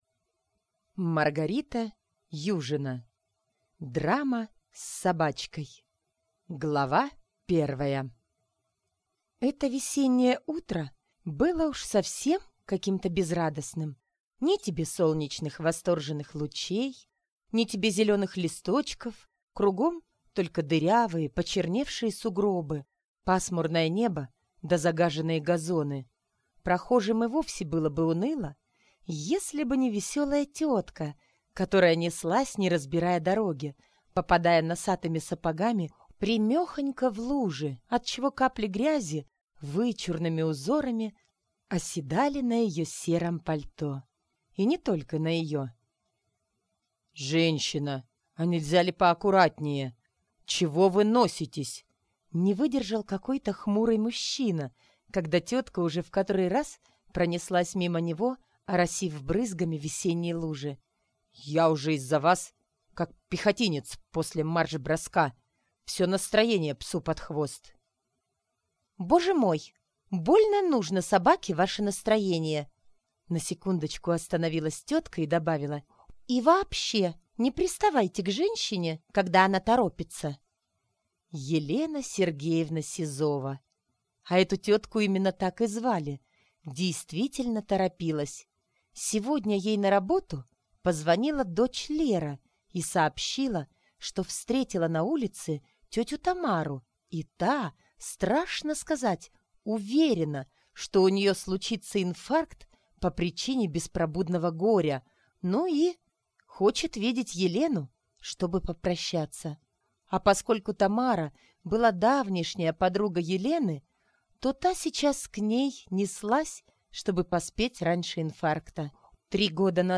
Аудиокнига Драма с собачкой | Библиотека аудиокниг